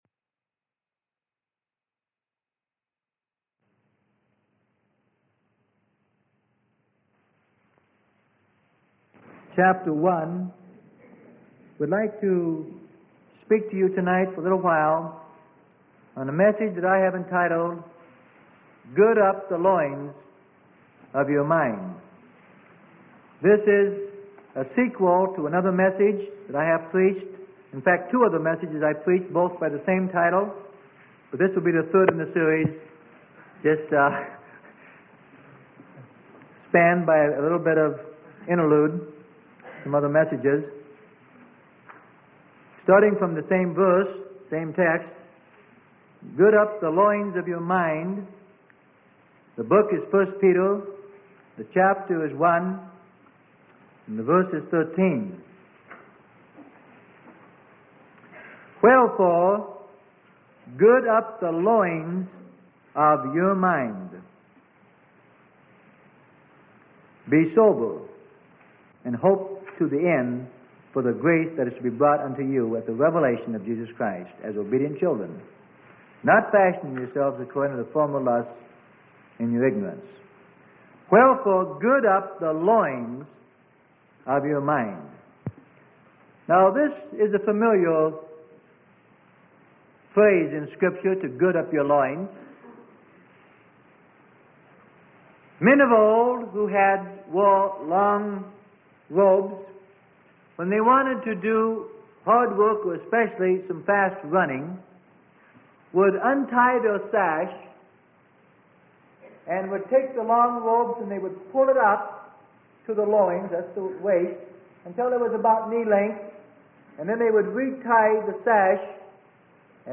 Sermon: Gird Up the Loins of your Mind - Freely Given Online Library